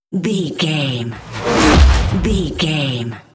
Sci fi whoosh to hit fast
Sound Effects
Atonal
Fast
dark
futuristic
intense
woosh to hit